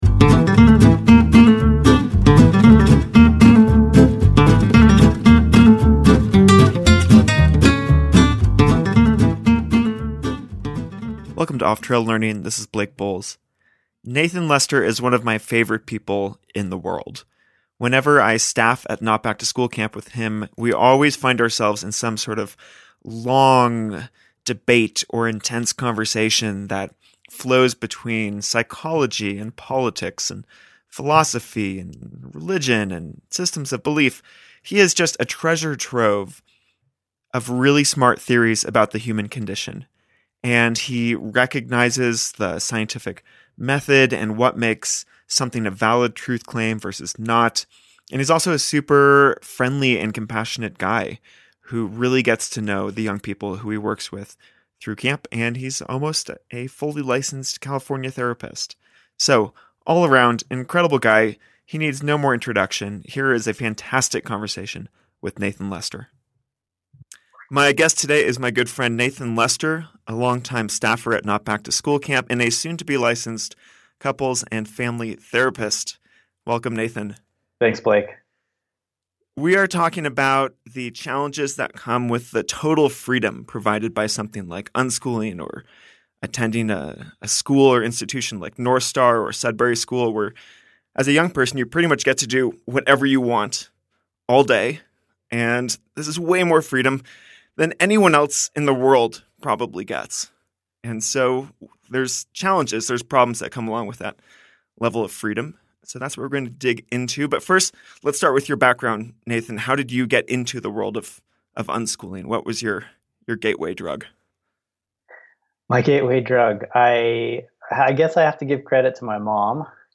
And what mental health concerns are unique to this group? Join us for a fascinating conversation about the ups and downs of total freedom.